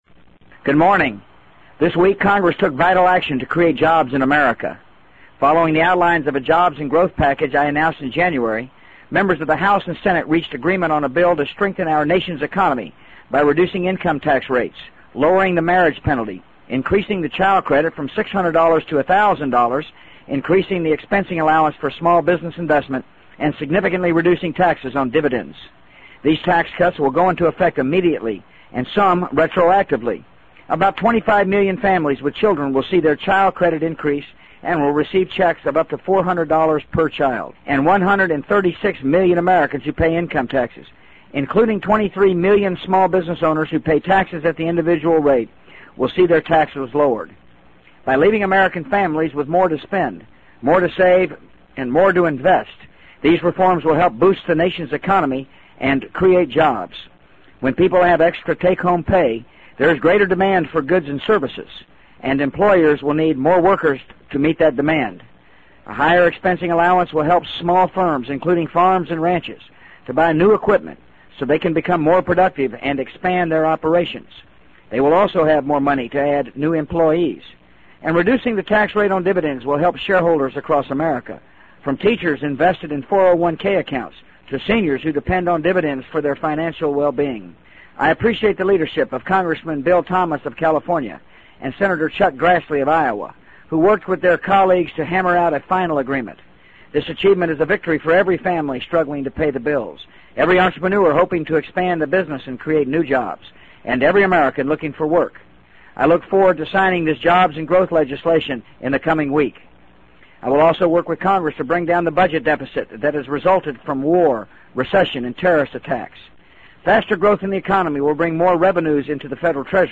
【美国总统George W. Bush电台演讲】2003-05-24 听力文件下载—在线英语听力室